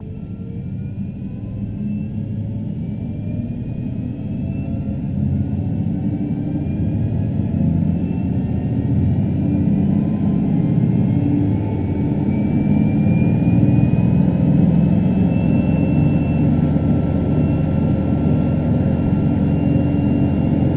从飞机内部起飞
描述：在飞机机舱内，从我们离开登机口到我们在空中的时候，
标签： 起飞 飞机客舱 飞机 发动机
声道立体声